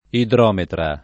idrometra [ idr 0 metra ]